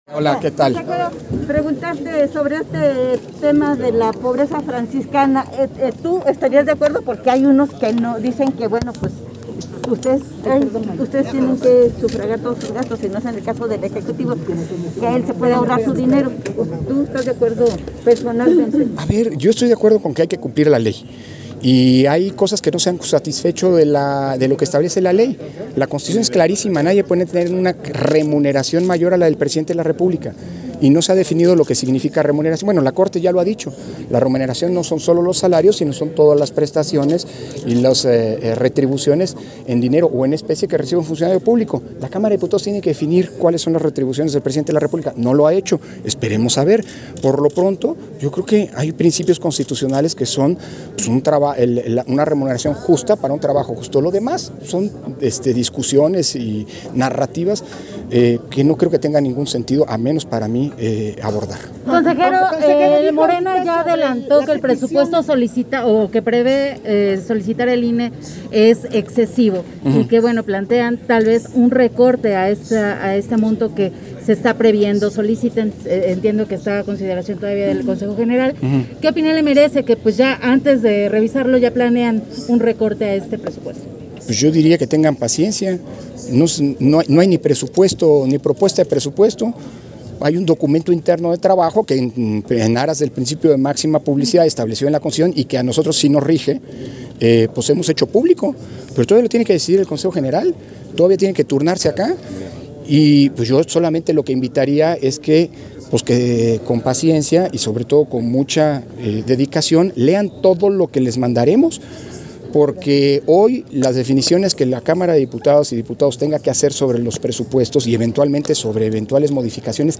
180822_AUDIO_ENTREVISTA-CONSEJERO-PDTE.-CORDOVA-CD - Central Electoral